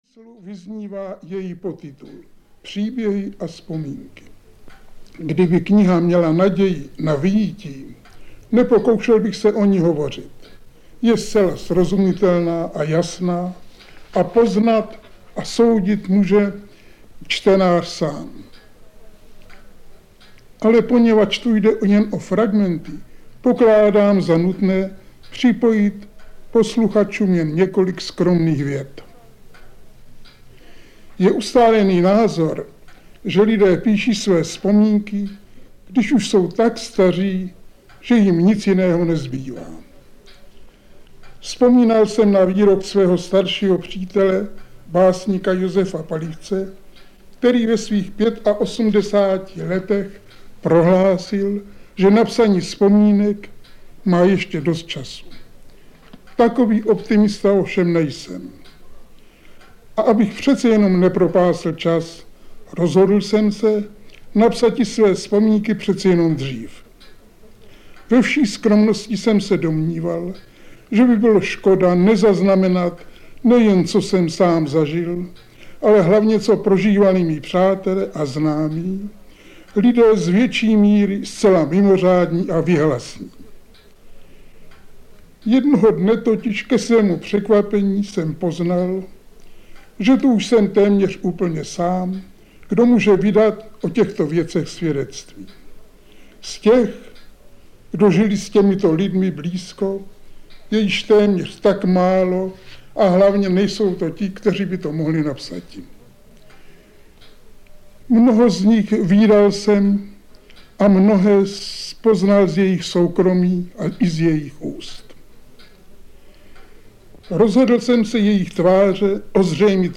Audio kniha
Část autorského čtení Seifertových pamětí natočil Vladimír Merta v roce 1978 a podařilo se mu přesvědčit i samotného básníka, aby nahrávku osobně zahájil svým úvodem.
• InterpretVlasta Chramostová, Jaroslav Seifert